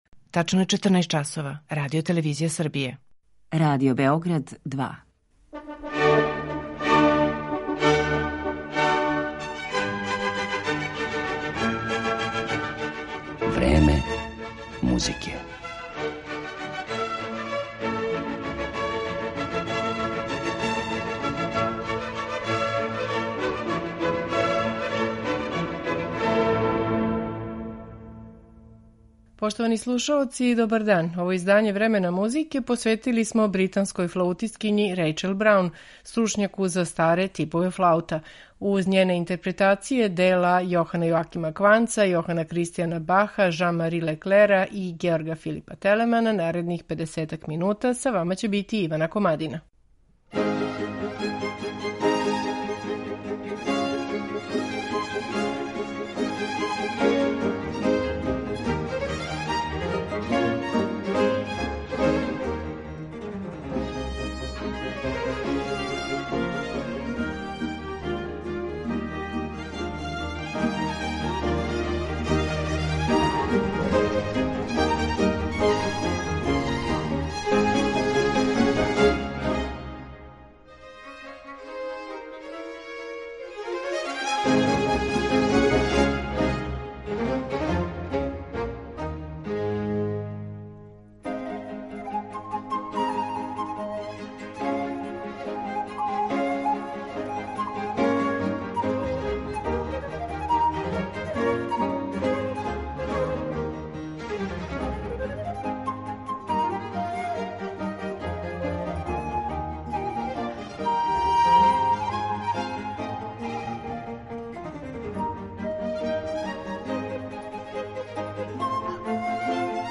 барокне музике на оригиналним инстурментима епохе
Ипак, најпознатија је по својим узбудљивим и виртуозним интерпретацијама оствареним на најразличитијим врстама блок-флаута и попречних флаута, као велики ауторитет праксе извођења на историјским инструментима.